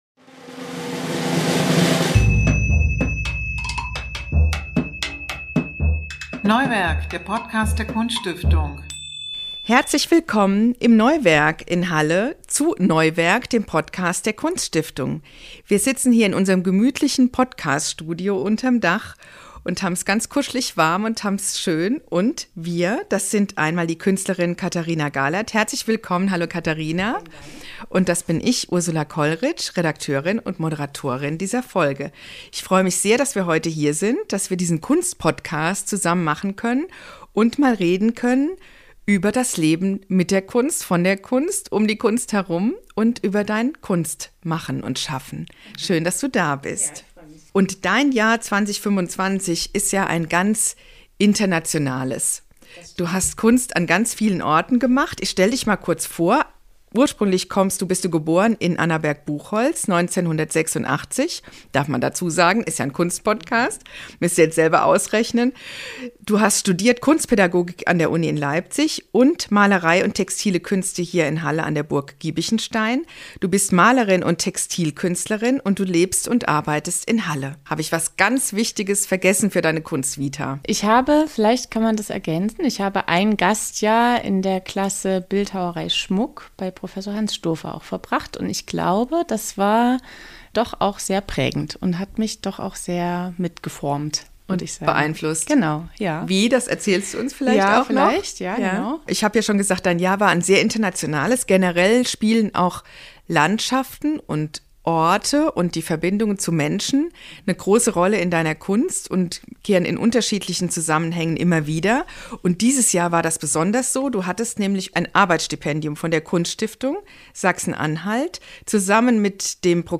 Gespräche über Kunst und die Welt